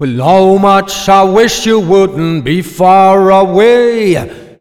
OLDRAGGA4 -R.wav